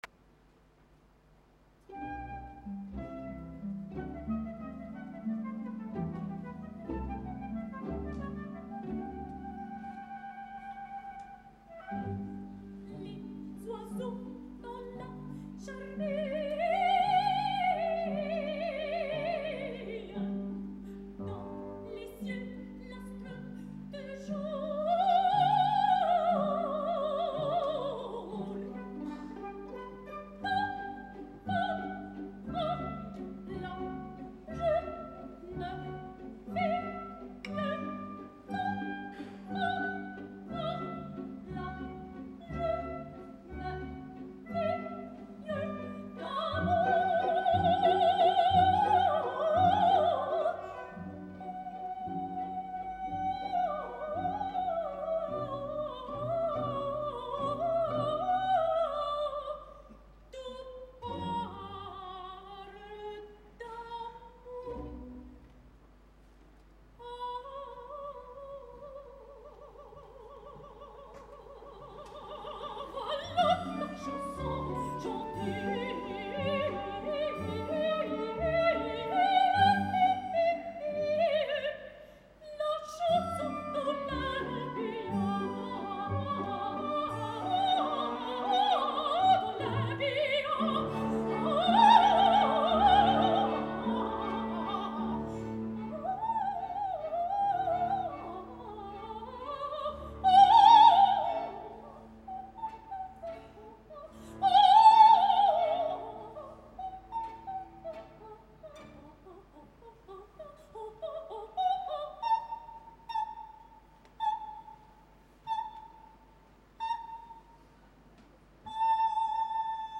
És una veu ample que està evolucionant de manera natural, per tant el més probable és que el repte d’assumir els quatre rols femenins per a soprano de Les Contes d’Hoffmann sigui una fita a curt o mig termini, però em penso que encara no. Quasi cap problema per l’Olympia, encara que el fet de fer repertoris més pesats ha fet que alguns atacs vocals i l’exhibició d’anys enrere no esdevingui el cristall pur que ens marevellava.